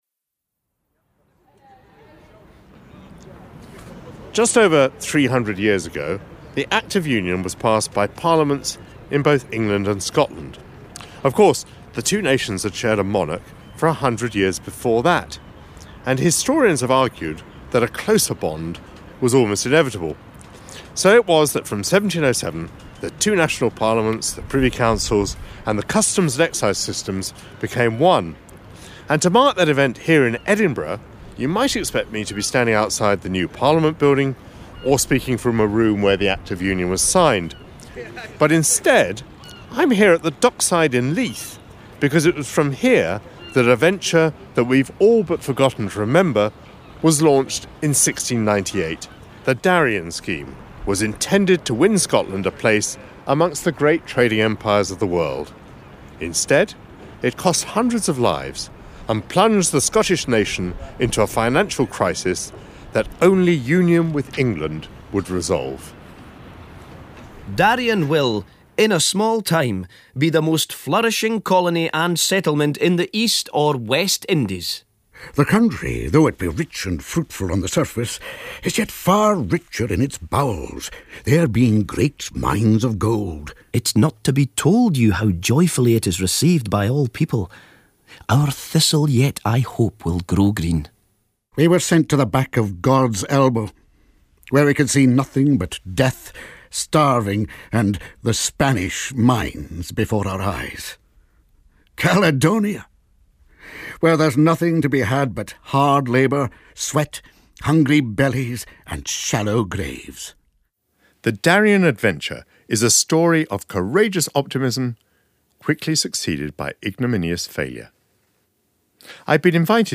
Michael Portillo and guests explore how a financial disaster led to the forging of the bonds of Union, for the BBC/Open University series The Things We Forgot to Remember . This edition was originally broadcast on BBC Radio 4 on 31st December 2007.